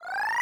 1select.wav